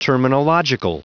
Prononciation du mot terminological en anglais (fichier audio)
Prononciation du mot : terminological